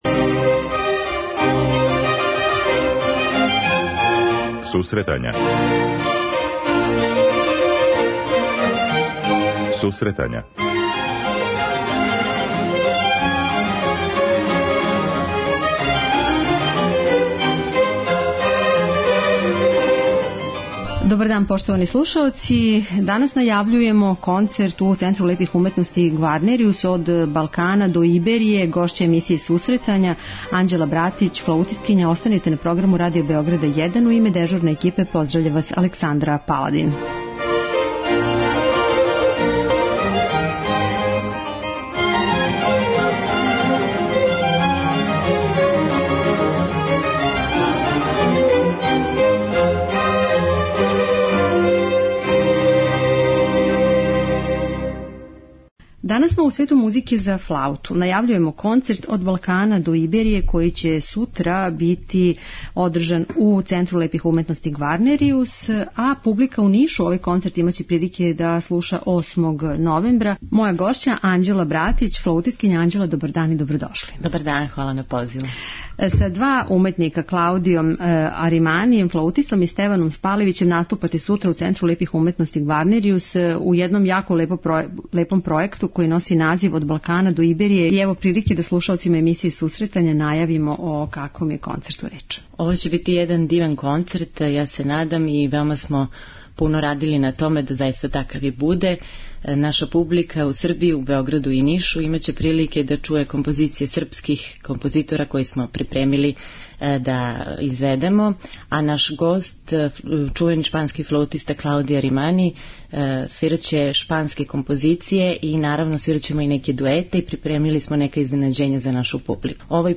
преузми : 10.67 MB Сусретања Autor: Музичка редакција Емисија за оне који воле уметничку музику.